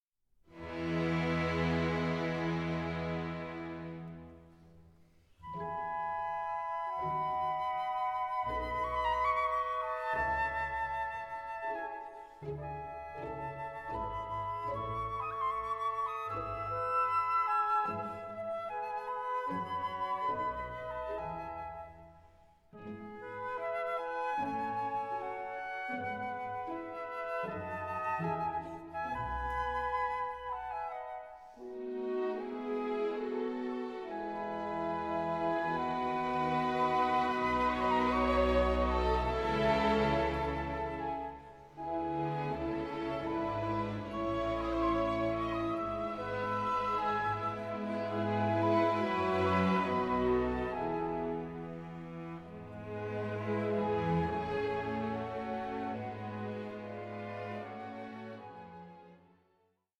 Classical transparency and Romantic drive